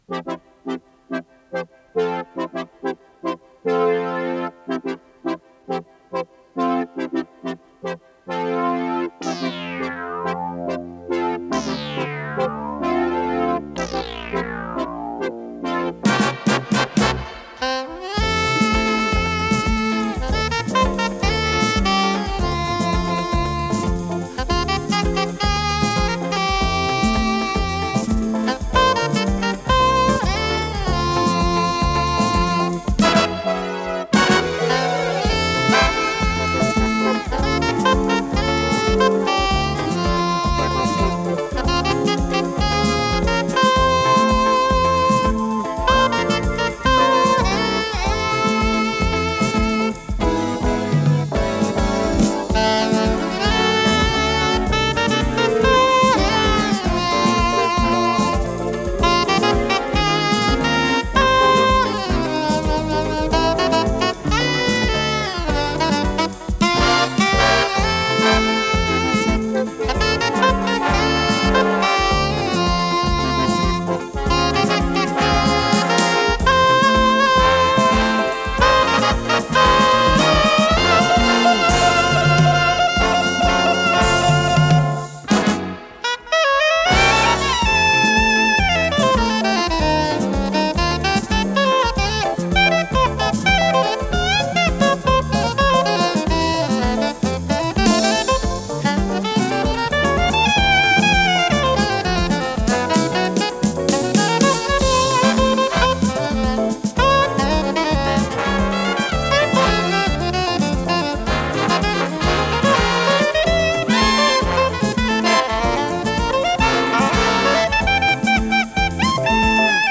с профессиональной магнитной ленты
саксофон-сопрано
ВариантДубль моно